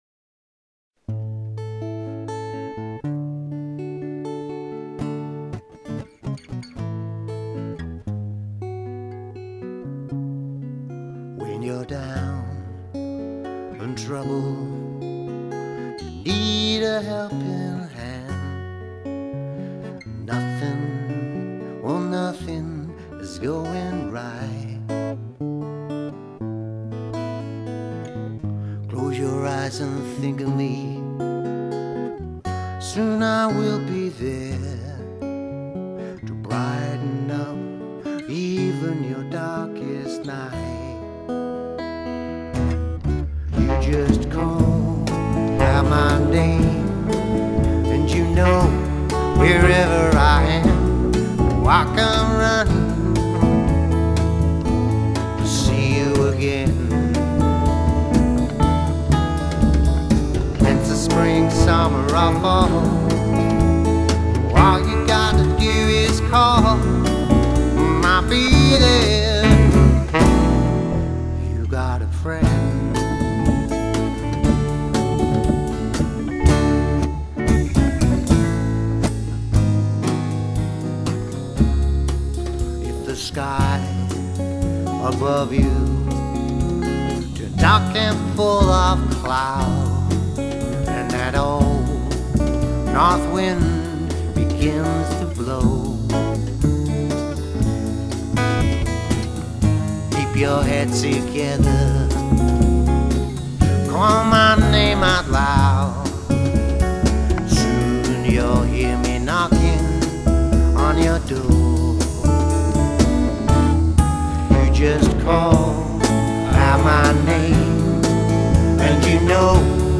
blues, jazz and easy listening
sax
bass
percussion
vocal and guitar
lead guitar